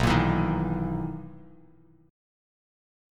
Listen to BM11 strummed